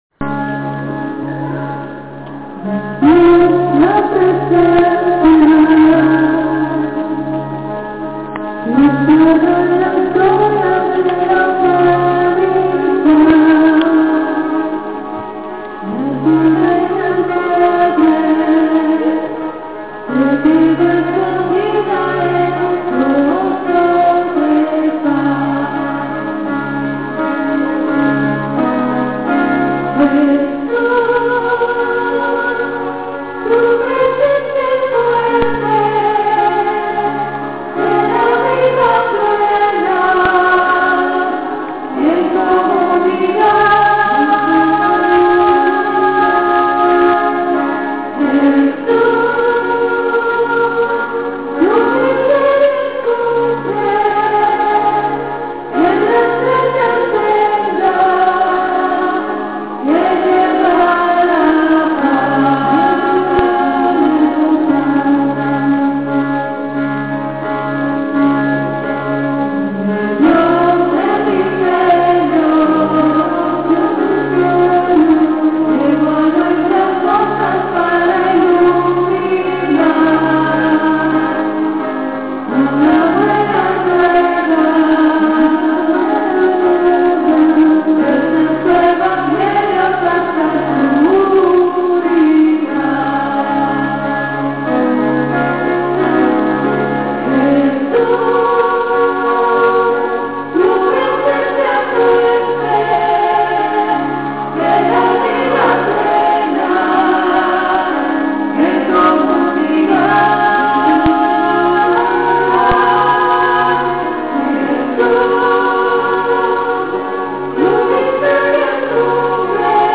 Coro
2A3. Presencia y Misterio CORO REC007.mp3